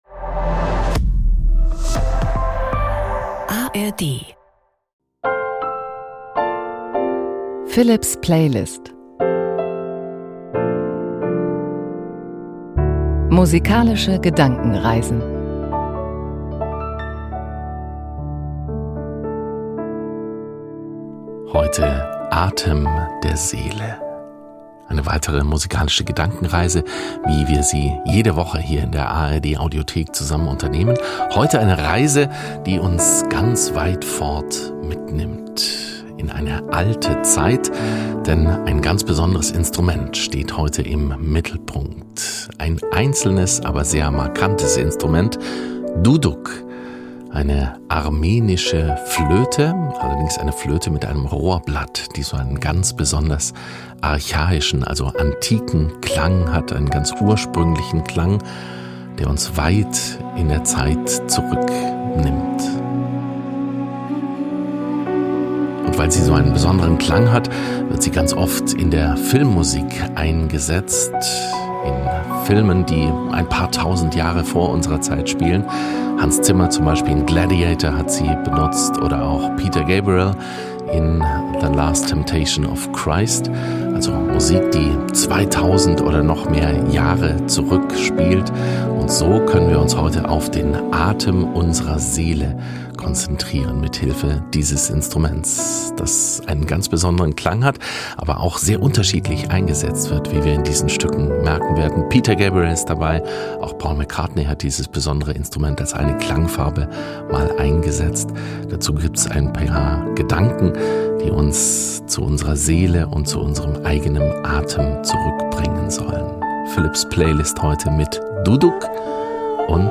Bekannt aus der Filmmusik.